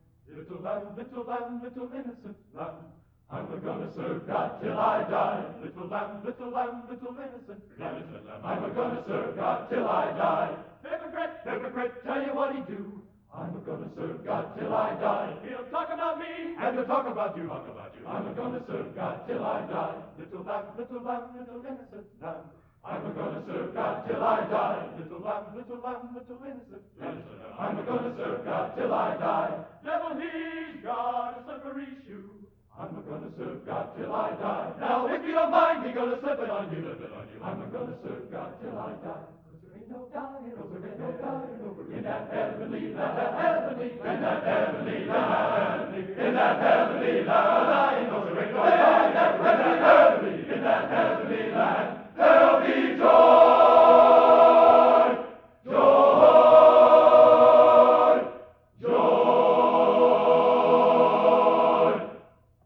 Genre: Spiritual | Type: End of Season